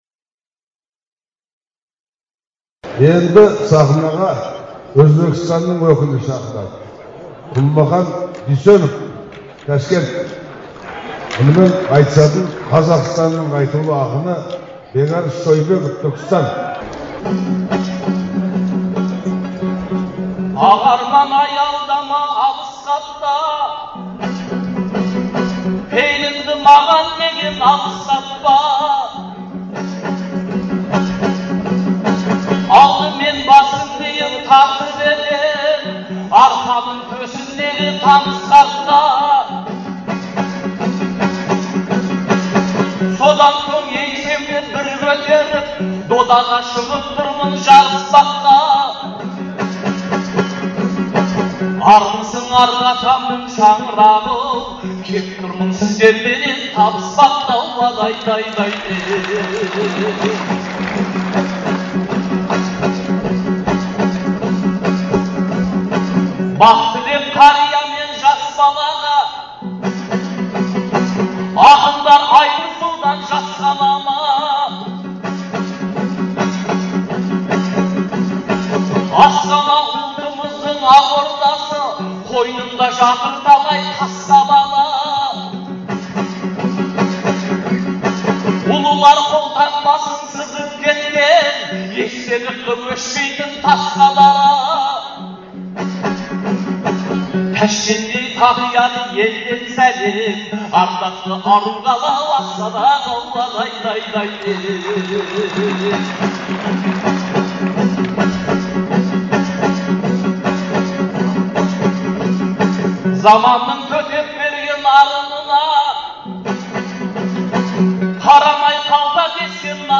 Астанадағы айтыс